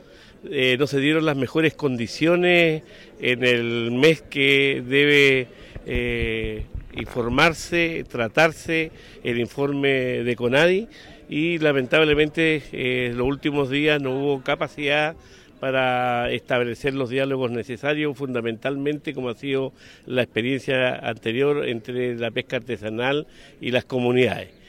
El gobernador de Los Ríos, Luis Cuvertino, atribuyó el rechazo de la solicitud a los informes técnicos, pero también aludió a la falta de diálogo previo entre los intervinientes.